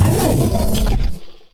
combat / creatures / cylarkan / she / attack1.ogg
attack1.ogg